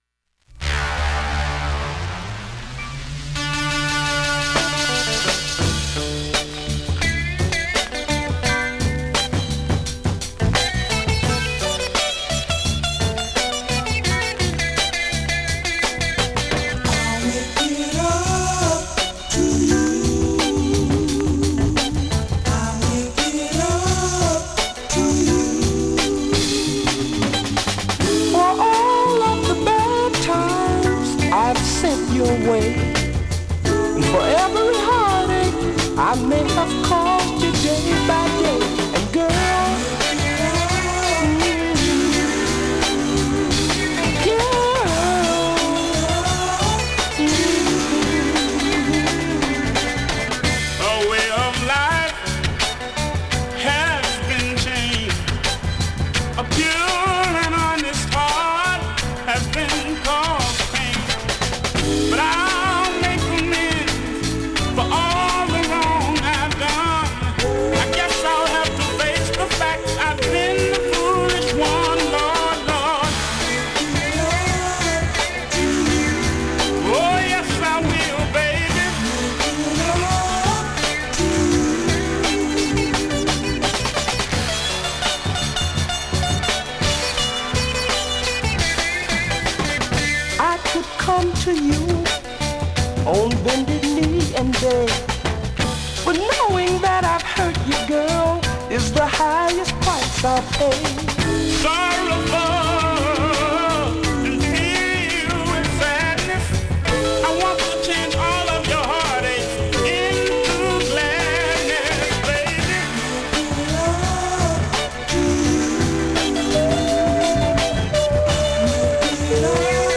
現物の試聴（両面すべて録音時間６分３０秒）できます。